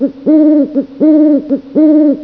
owl.wav